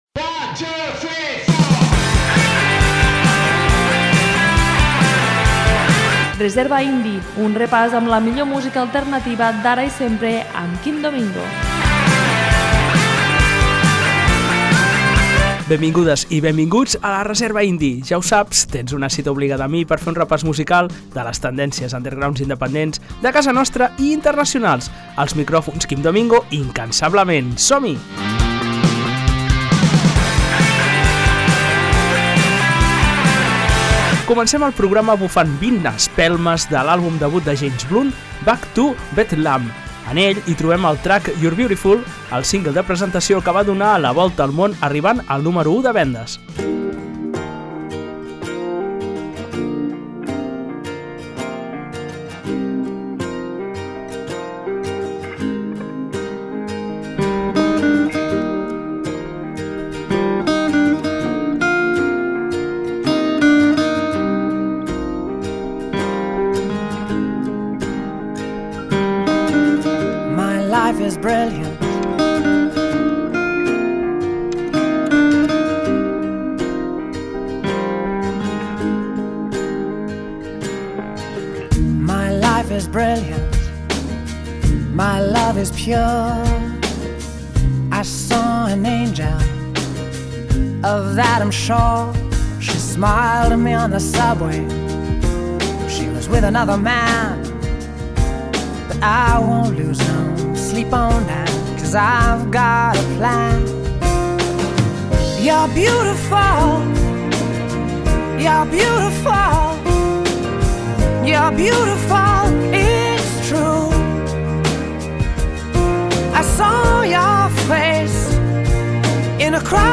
En el senzill combina la seva faceta guitarrera amb els ritmes electrònics.